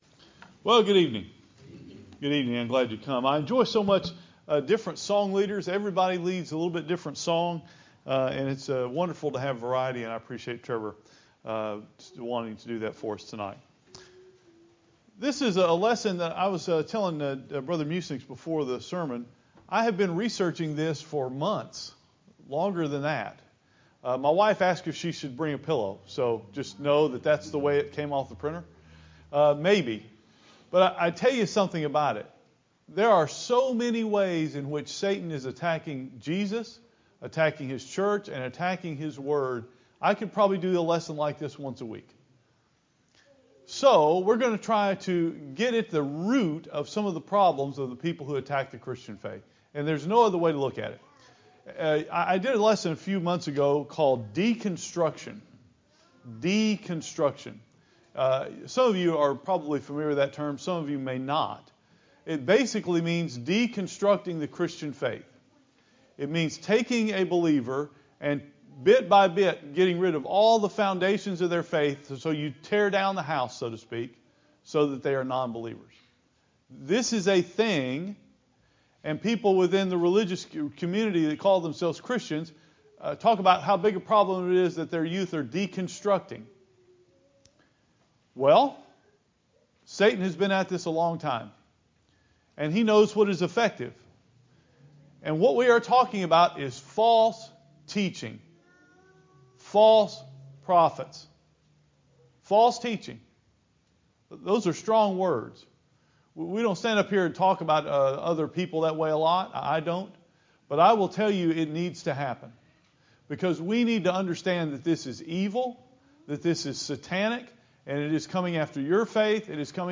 I did not mention names in this sermon for two reasons: